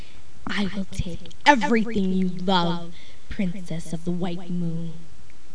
Sense we haven't started the dub yet I put up audition clips.